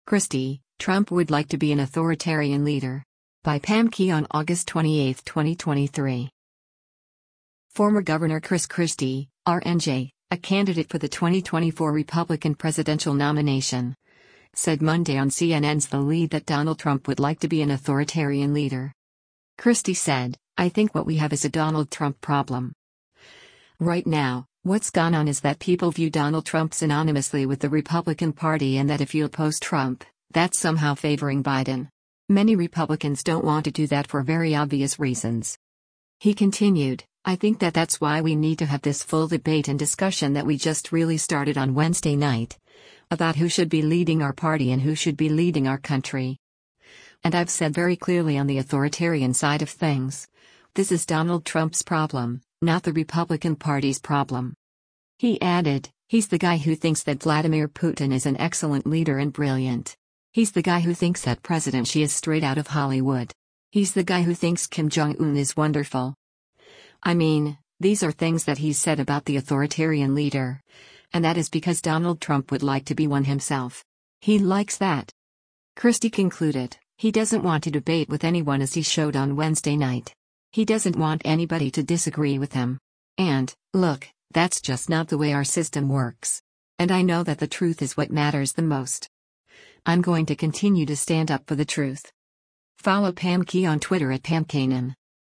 Former Gov. Chris Christie (R-NJ), a candidate for the 2024 Republican presidential nomination, said Monday on CNN’s “The Lead” that Donald Trump would like to be an “authoritarian leader.”